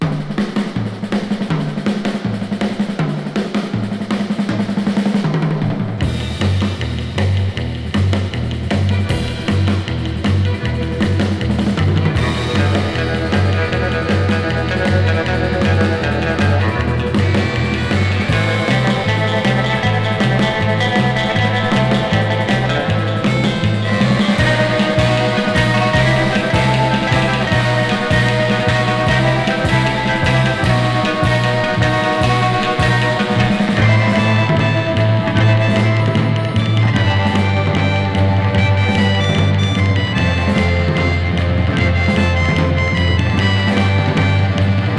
Surf, Rock & Roll　USA　12inchレコード　33rpm　Stereo